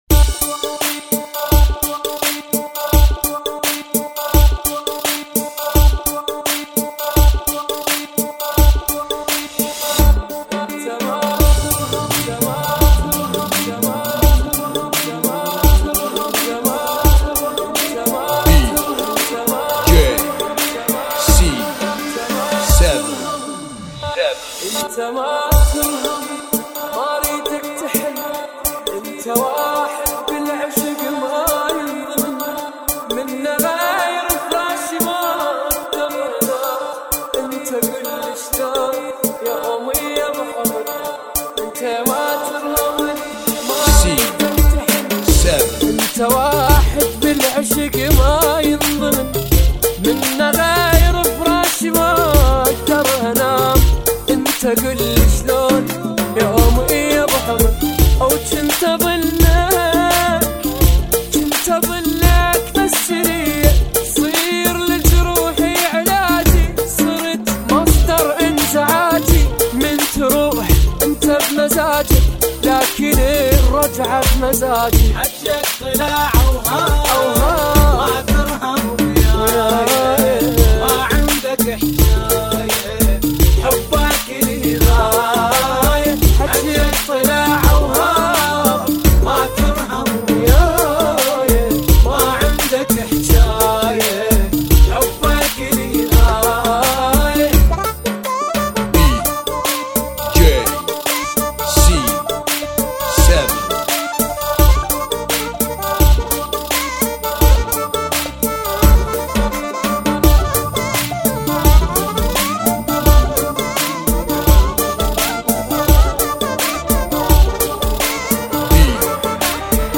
BPM 85